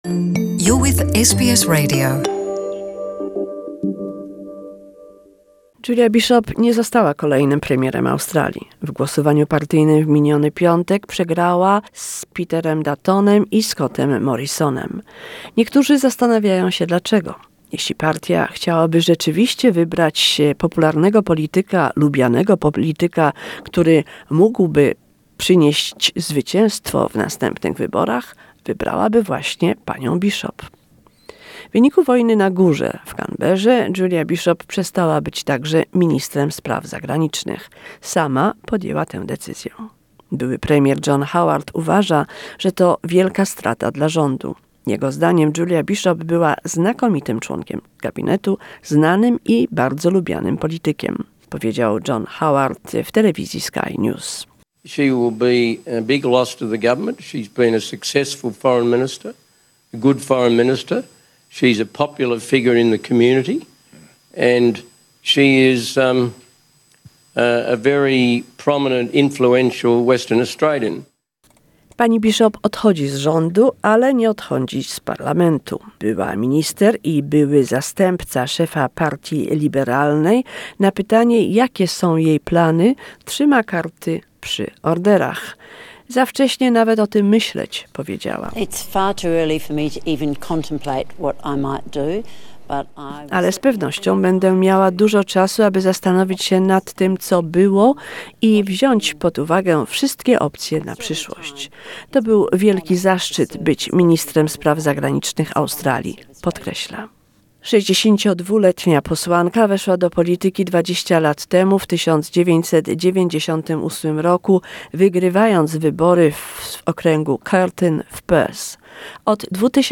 Julie Bishop's last press conference as foreign minister